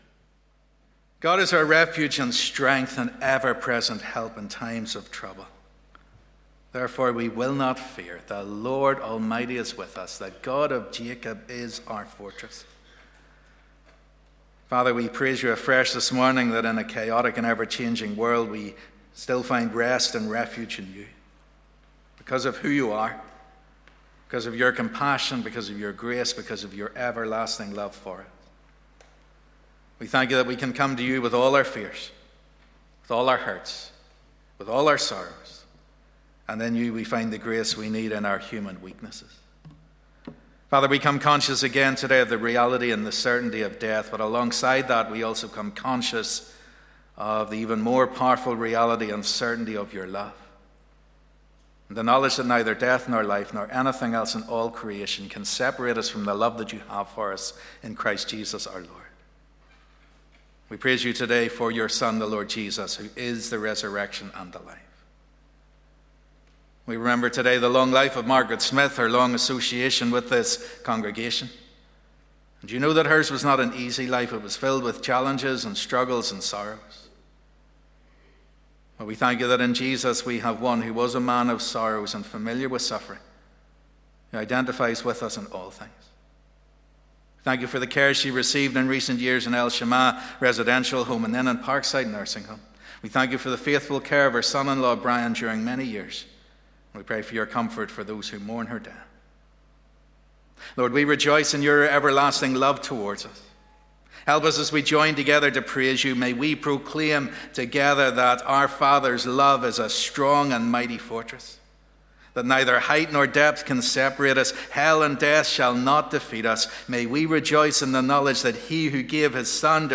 This morning the members of the Exodus work team who recently returned from the Exodus Centre in Sutor Romania will report back to the congregation on the work that they were able to complete and the experiences that they enjoyed.